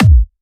VEC3 Bassdrums Trance 25.wav